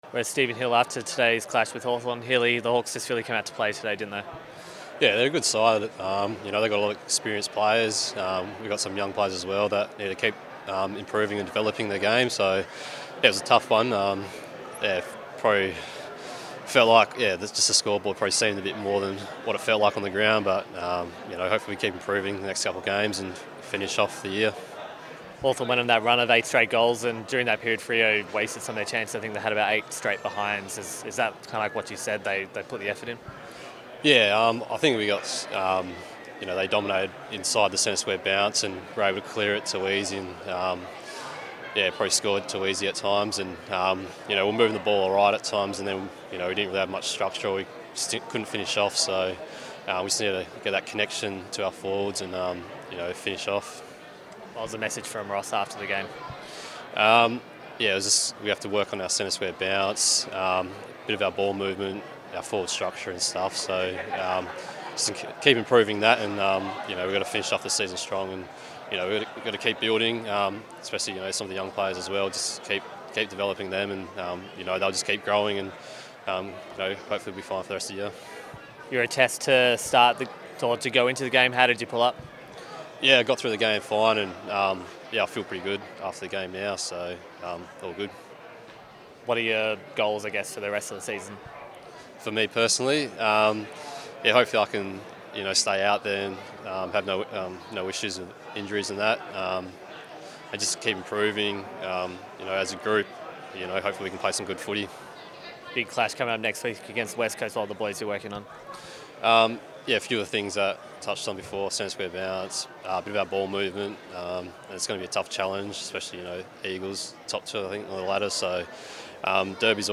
Stephen Hill chats to DockerTV after the clash against the Hawks.